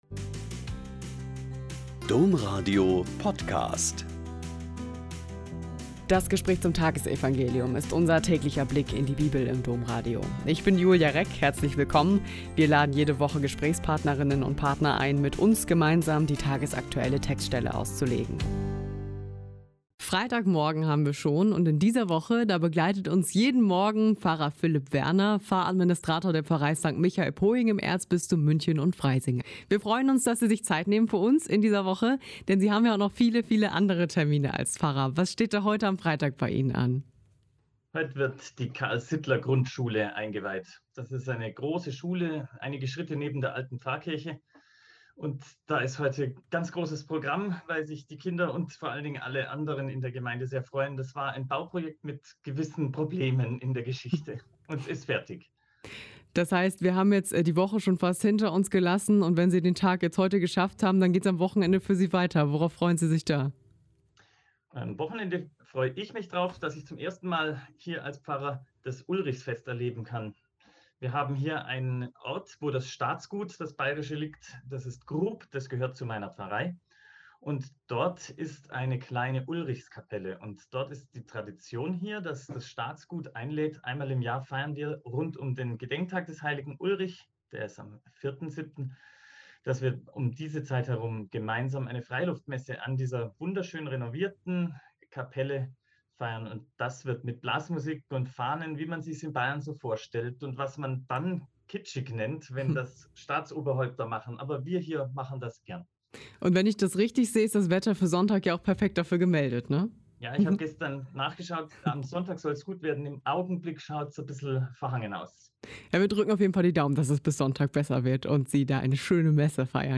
Mt 9,9-13 - Gespräch